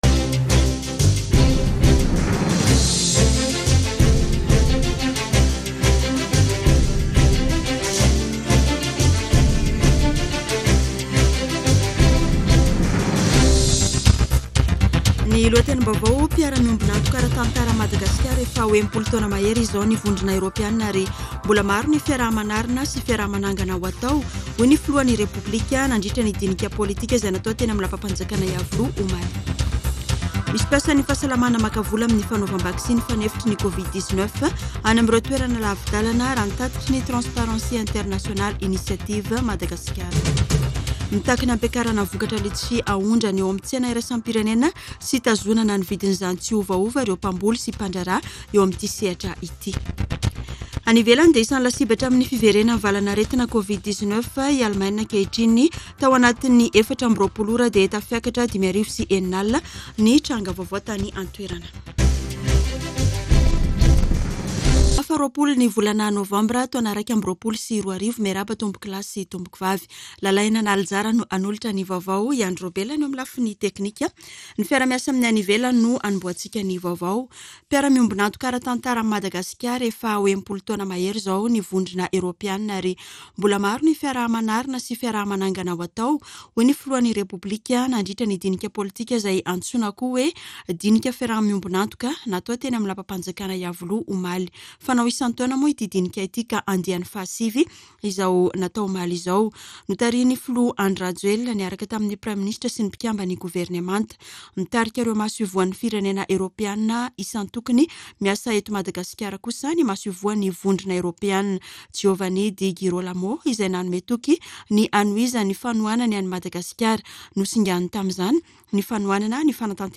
[Vaovao maraina] Sabotsy 20 novambra 2021